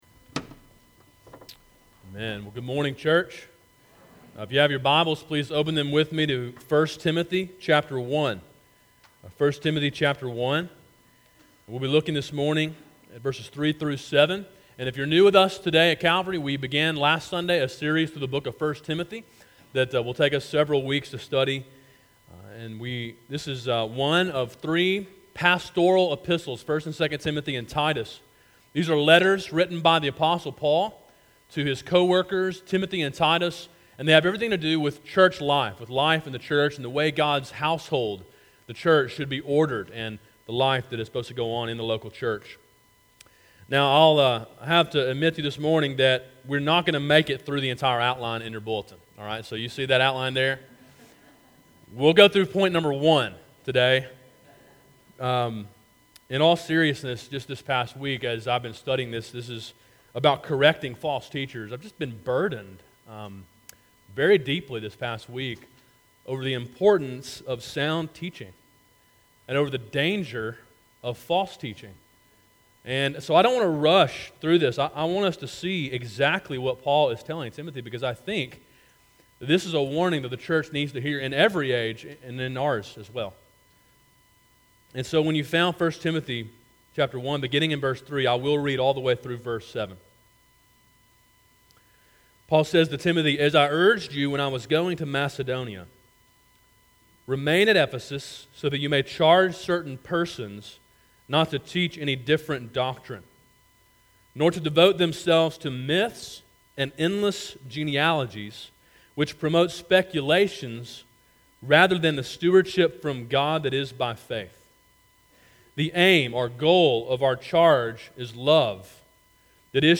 A sermon in a series through the book of 1 Timothy.